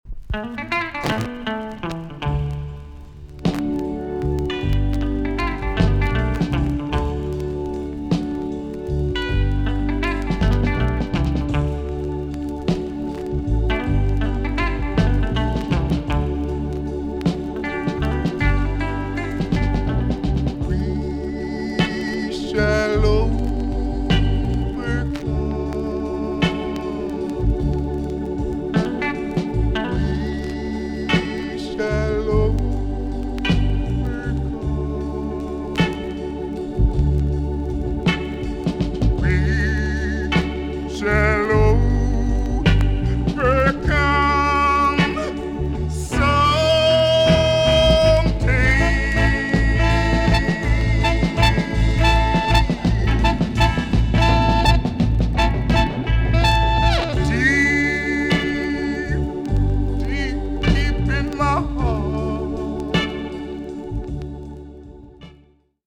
TOP >REGGAE & ROOTS
EX-~VG+ 少し軽いチリノイズがありますが良好です。